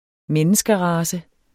Udtale [ -ˌʁɑːsə ]